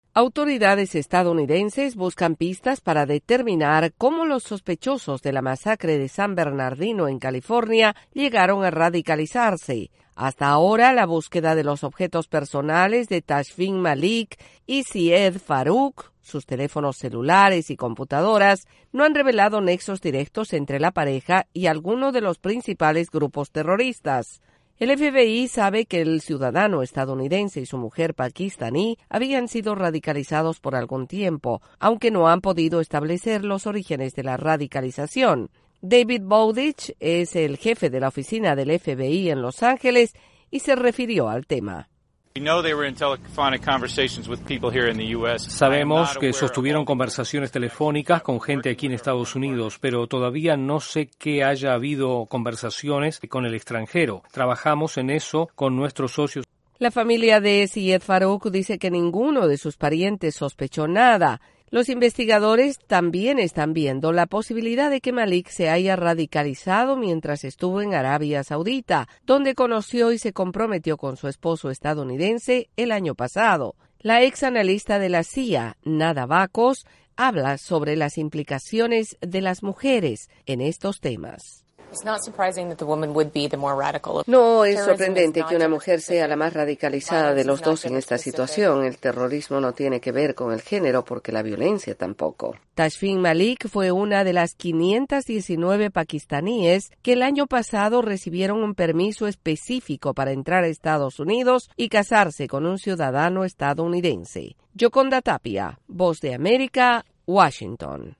Autoridades de diversas agencias del gobierno de Estados Unidos continúan indagando sobre los perpetradores del ataque de San Bernardino. Desde la Voz de América en Washington informa